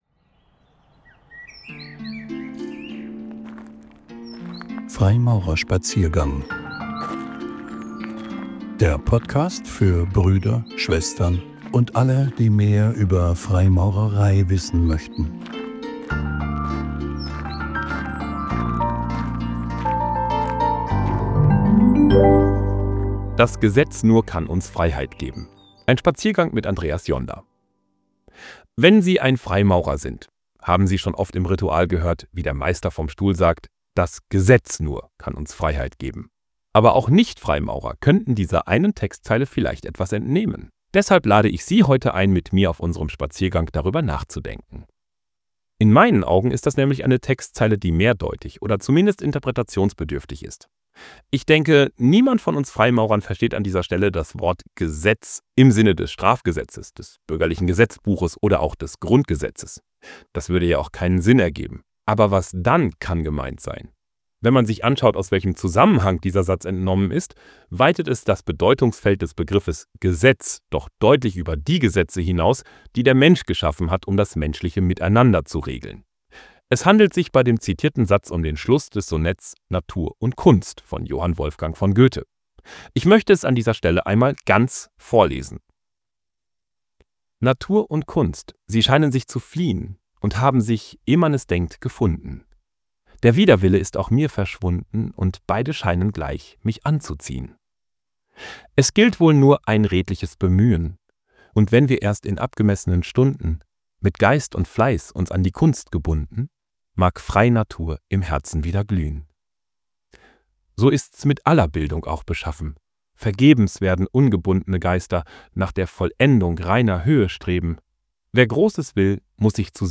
Ein Vortrag